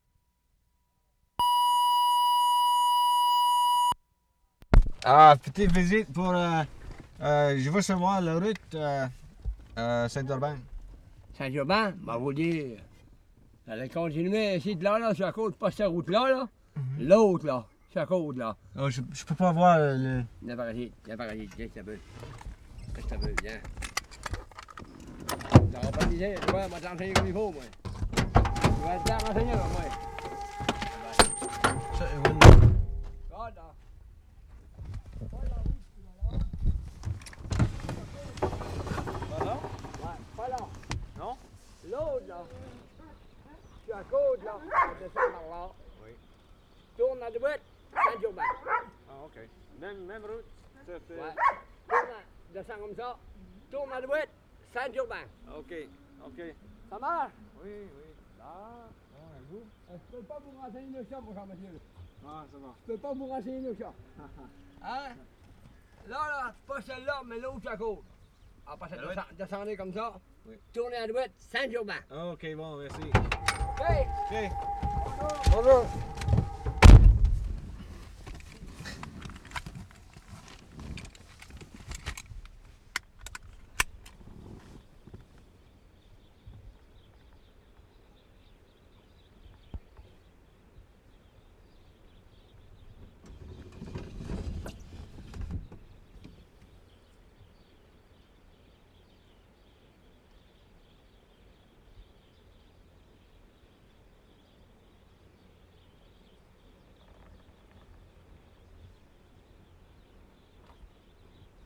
WORLD SOUNDSCAPE PROJECT TAPE LIBRARY
ST. LAURENT - NORTH SHORE, QUEBEC Oct. 26, 1973